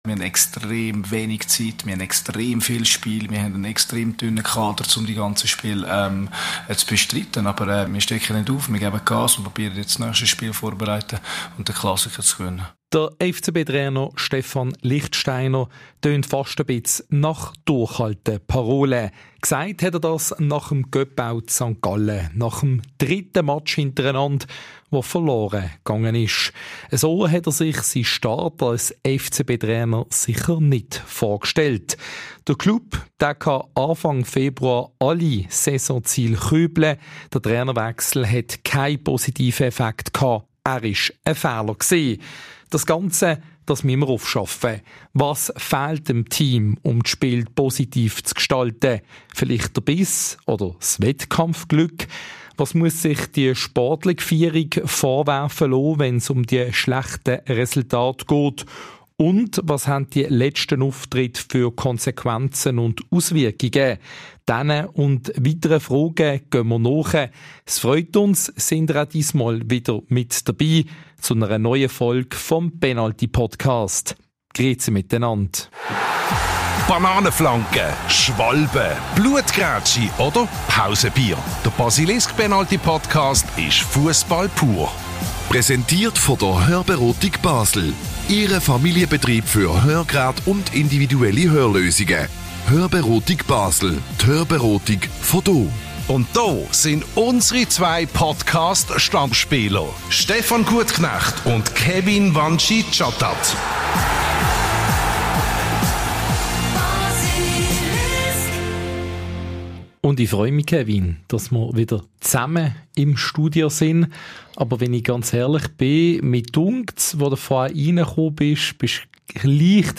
Unser Podcast-Duo blickt nochmals zurück auf das bittere Ausscheiden in St. Gallen und fragt sich, weshalb die Ostschweizer am Ende mehr Biss und Power hatten.